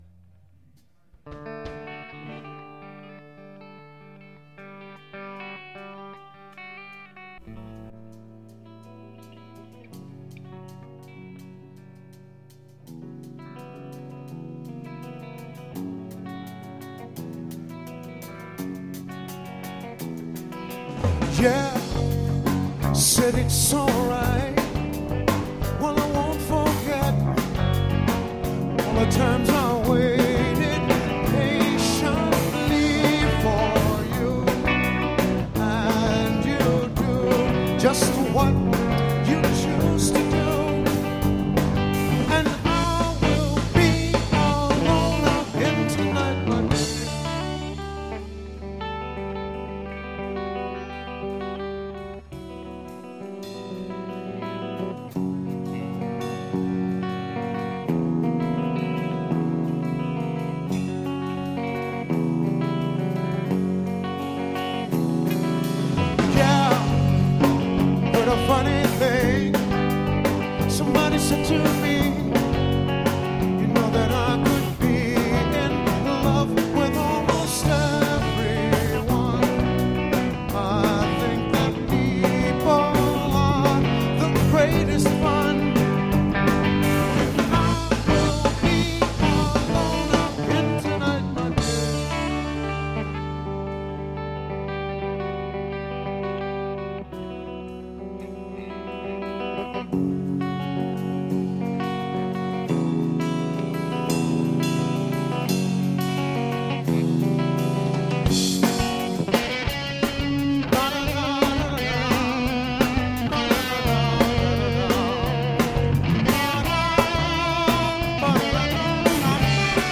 in concert at Northampton Center For The Arts
Band Soundboard